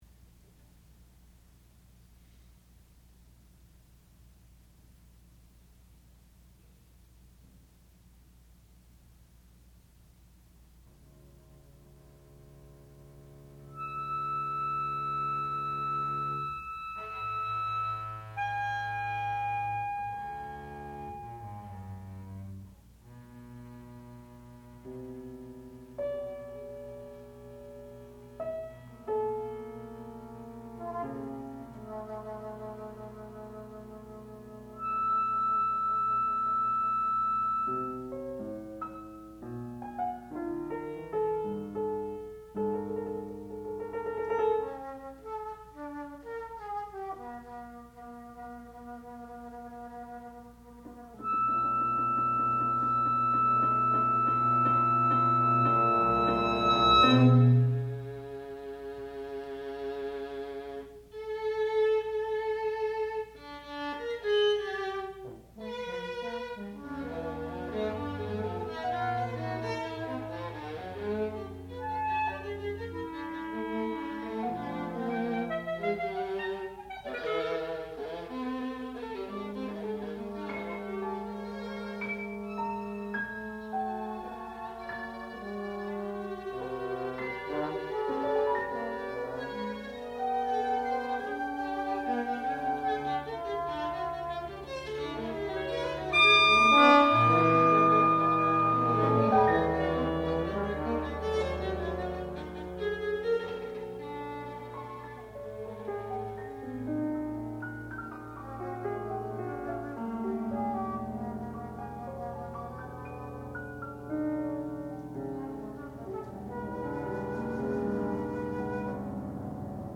sound recording-musical
classical music
Graduate Recital